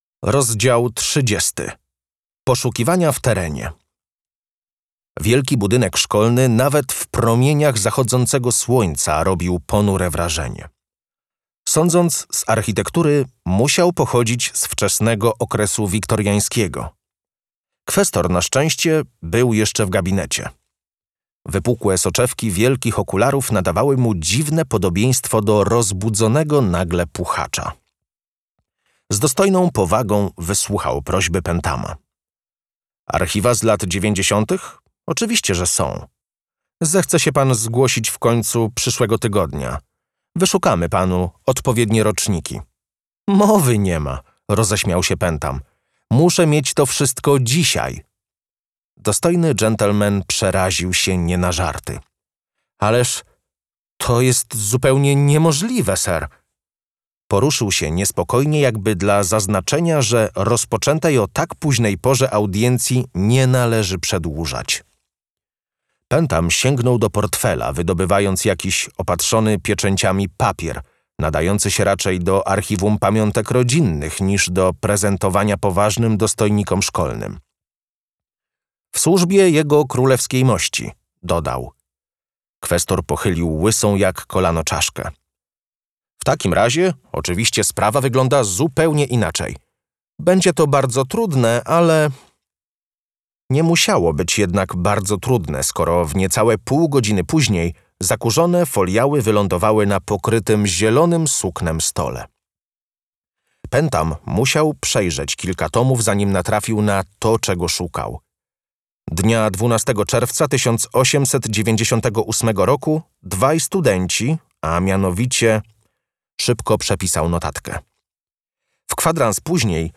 DEMO AUDIOBOOK 1: